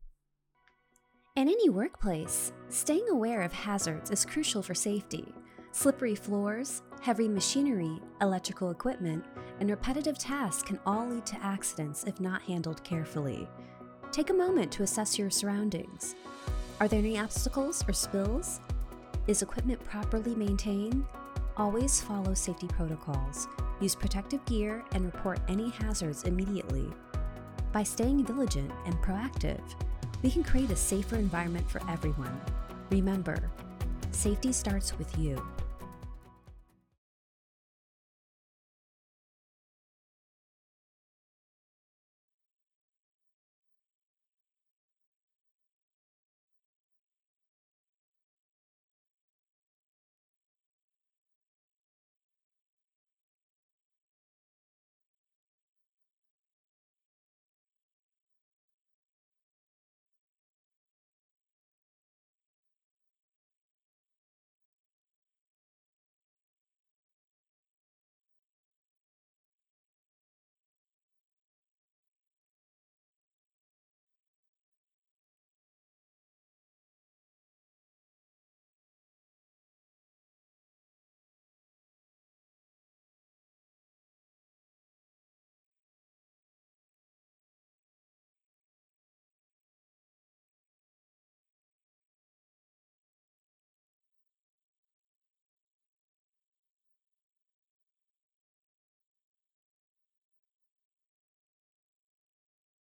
Voice-over artist with a warm, articulate, and soothing voice that brings calm and clarity to every project
Workplace Safety Explainer